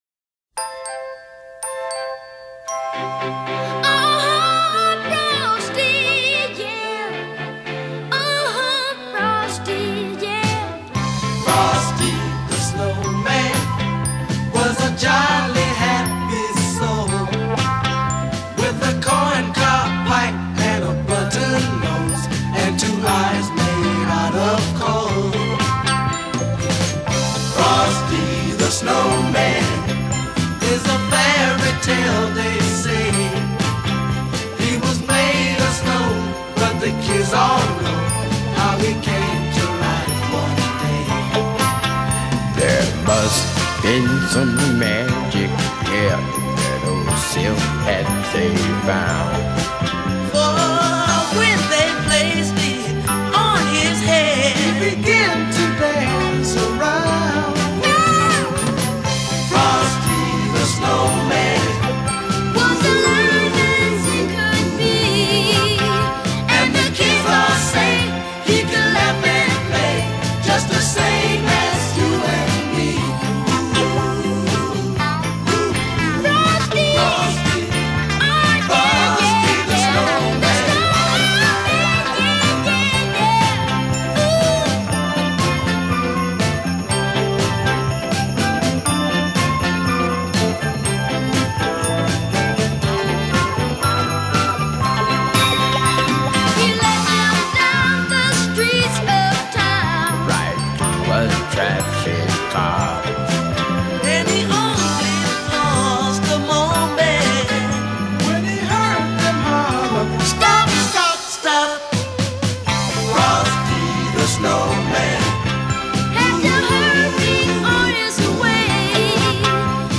类　　别: Christmas, Pop　　　　　　　　　　　　　　　.